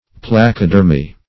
Placodermi \Plac`o*der"mi\, n. pl.